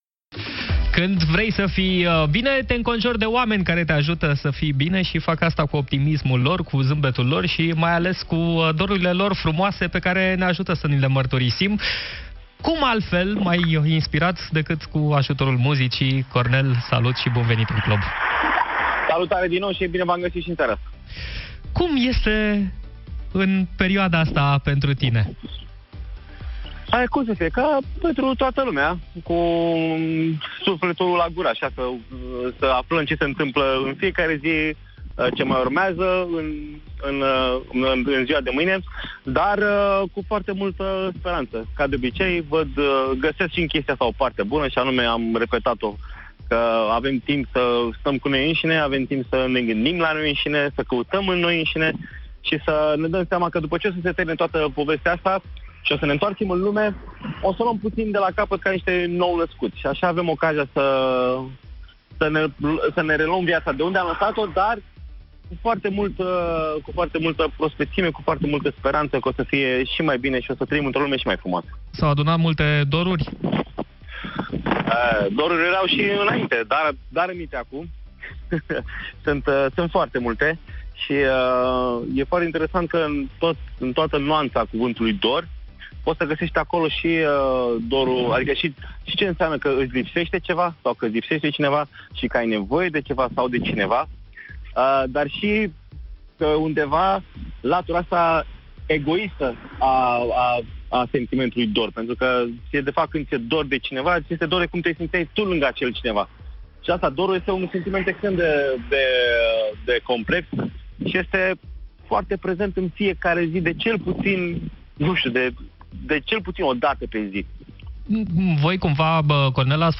În direct în Clubul de Seară, Cornel Ilie a vorbit despre cum va fi marcată în acest an Ziua Dorului, pe 13 mai, o sărbătoare creată de trupa Vunk în 2015.
Motivele din spatele acestei alegeri au fost explicate  în direct la Europa FM: